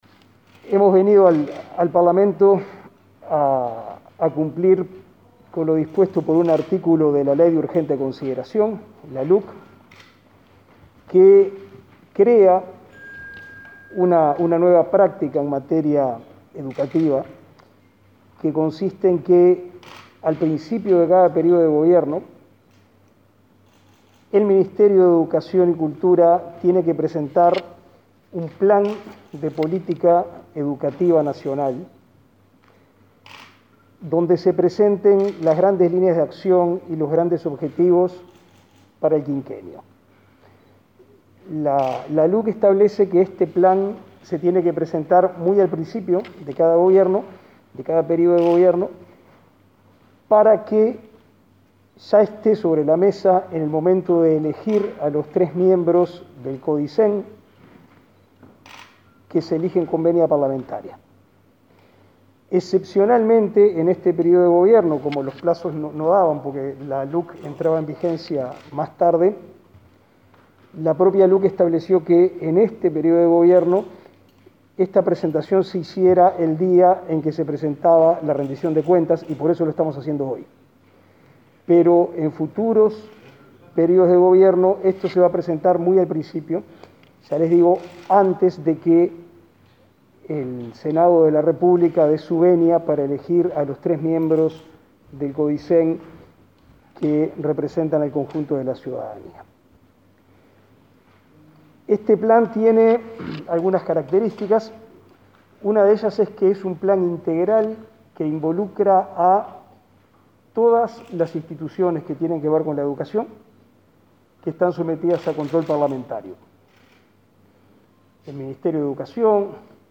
Declaraciones del ministro de Educación y Cultura, Pablo da Silveira